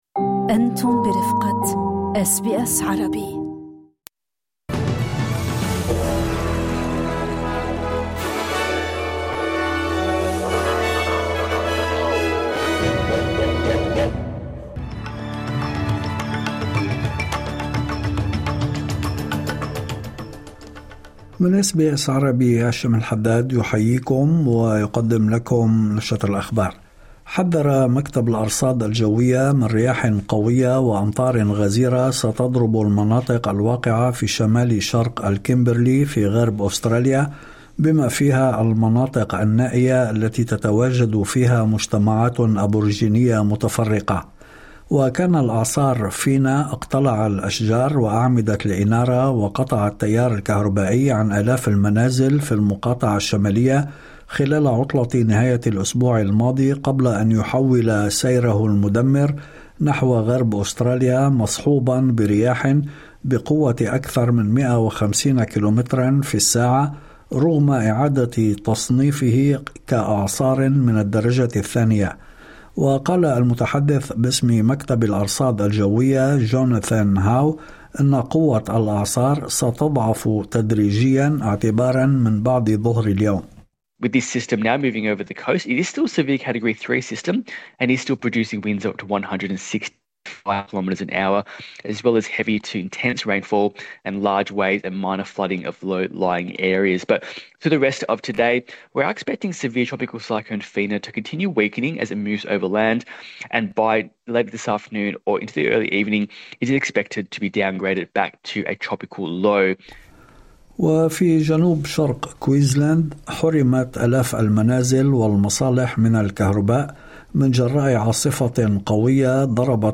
نشرة أخبار الظهيرة 25/11/2025